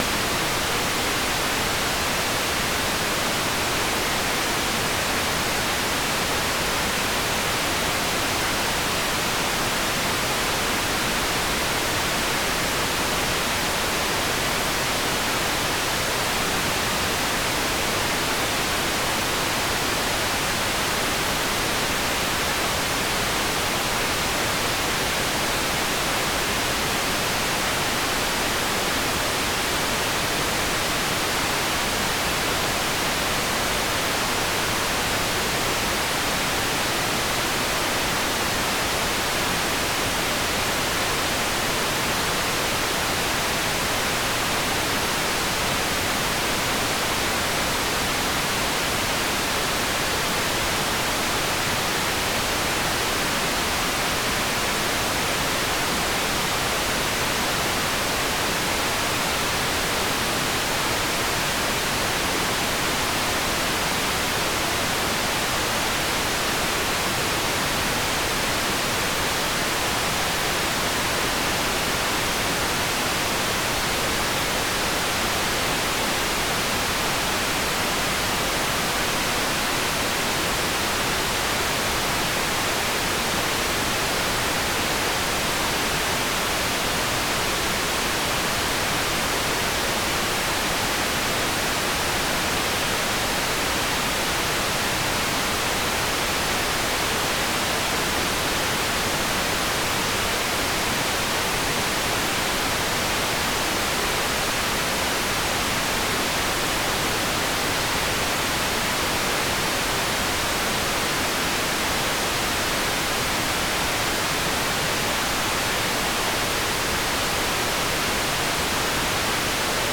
"transmitter_mode": "APT",